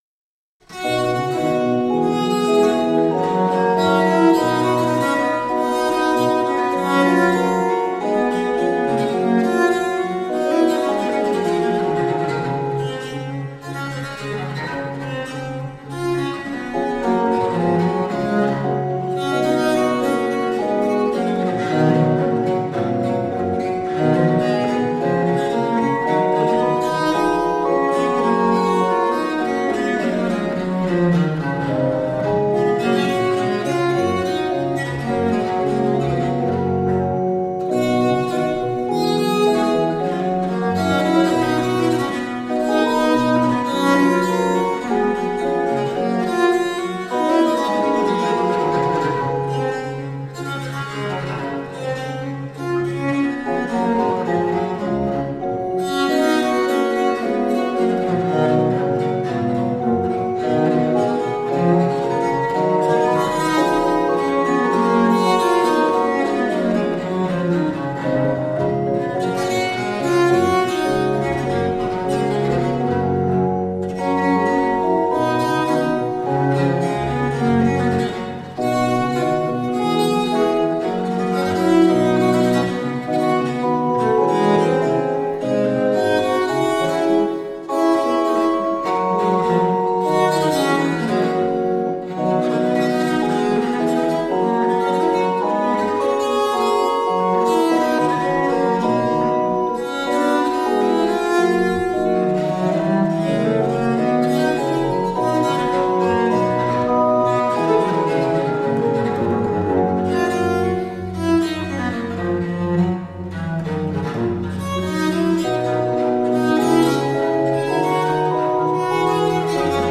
German Sonatas for Viola da Gamba from the Baroque period.